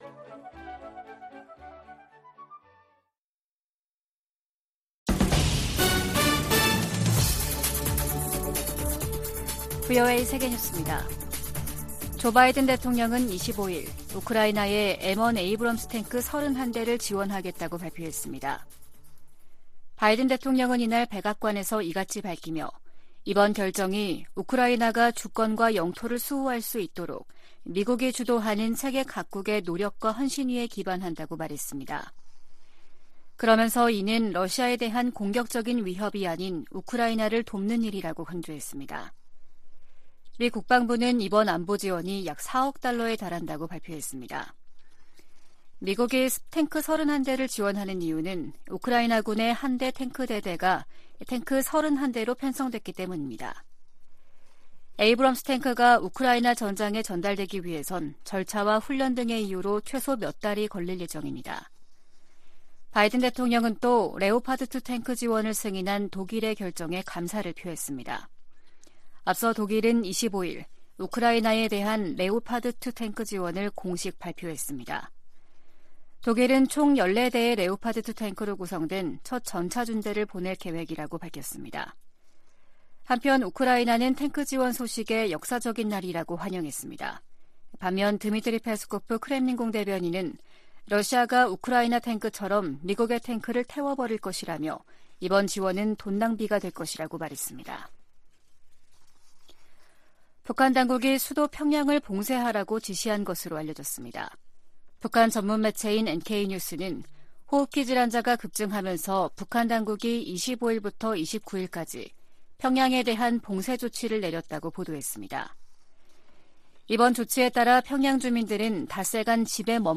VOA 한국어 아침 뉴스 프로그램 '워싱턴 뉴스 광장' 2023년 1월 26일 방송입니다. 미 국무부는 줄리 터너 북한인권특사 지명자에 대한 조속한 상원 인준을 촉구했습니다.